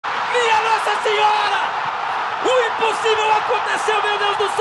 o impossivel aconteceu meu deus do ceu Meme Sound Effect
o impossivel aconteceu meu deus do ceu.mp3